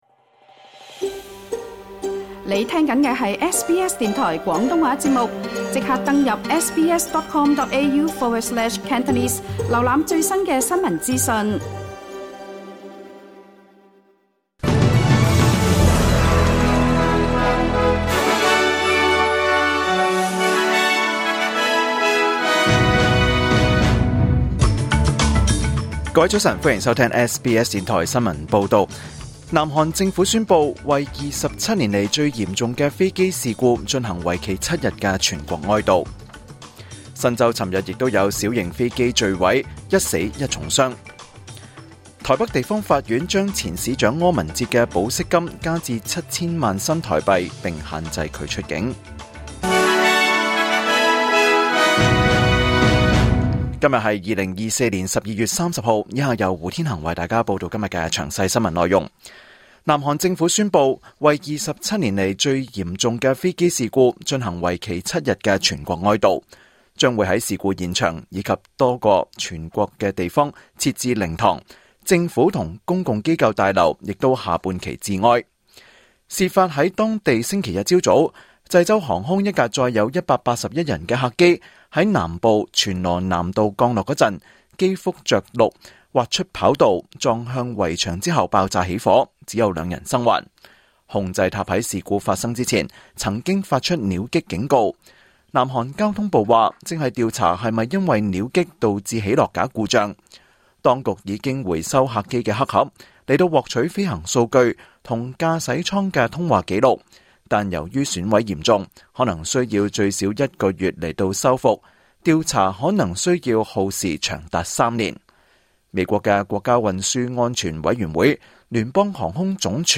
2024年12月30日SBS 廣東話節目詳盡早晨新聞報道。